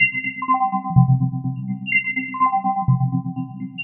tx_synth_125_ching_CFG1.wav